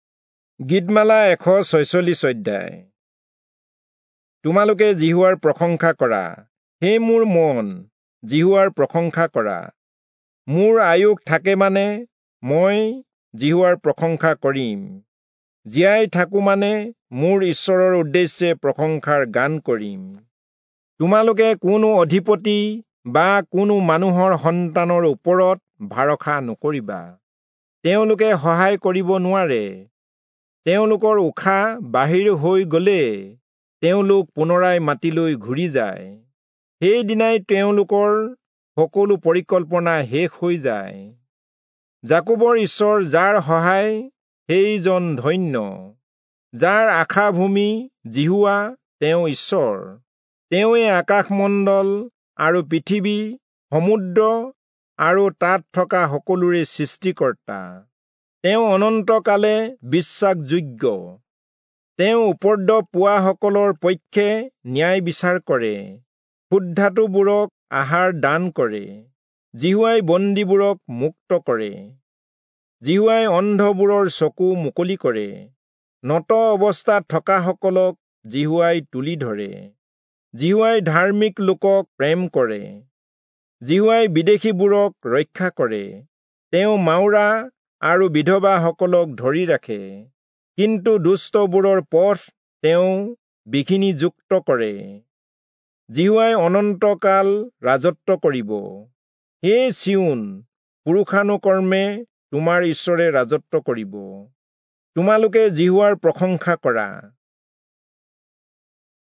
Assamese Audio Bible - Psalms 4 in Erven bible version